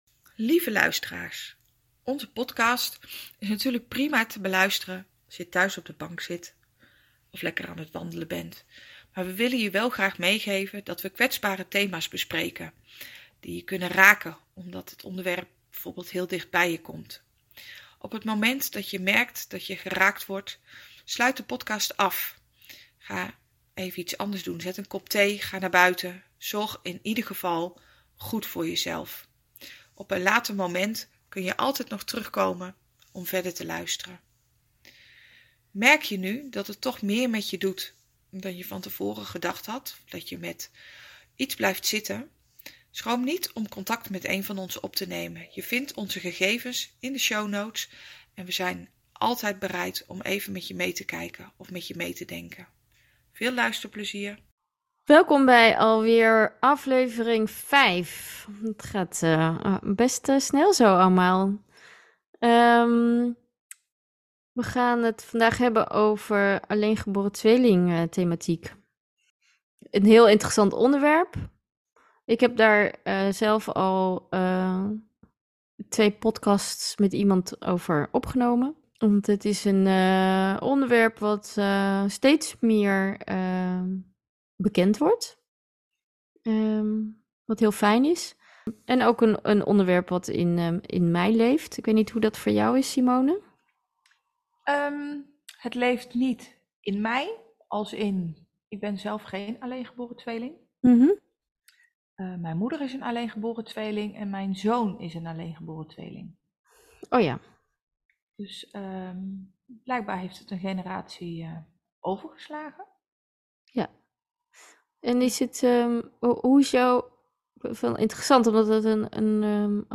In inspirerende gesprekken delen zij hun kennis en ervaringen uit hun eigen praktijk.